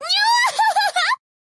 >このにょわほほほ言ってるお姉さんの声沢城みゆきなんだな
作者がちゃんと自然な笑い声になるんだなって感心していたやつ